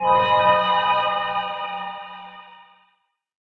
cave.wav